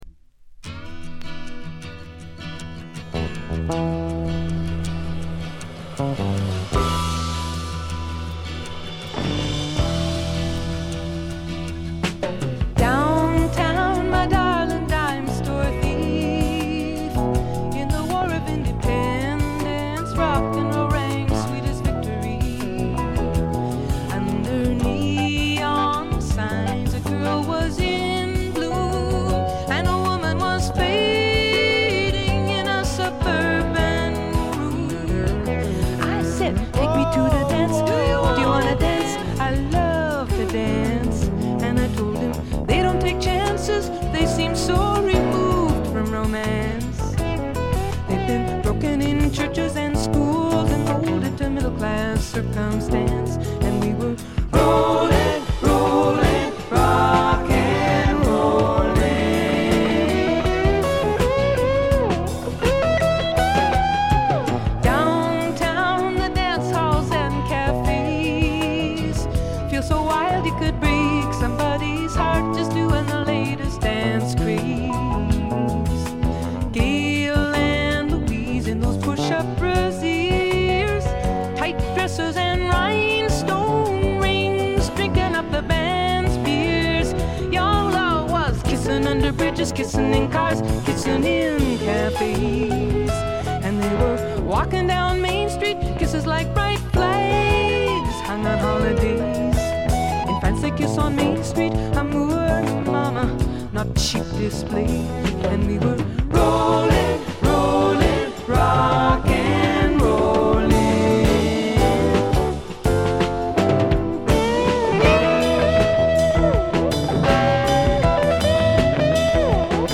ディスク:部分試聴ですがほとんどノイズ感無し。極めて良好に鑑賞できると思います。実際の音源を参考にしてください。
ここからが本格的なジャズ／フュージョン路線ということでフォーキーぽさは完全になくなりました。
女性シンガーソングライター名作。
試聴曲は現品からの取り込み音源です。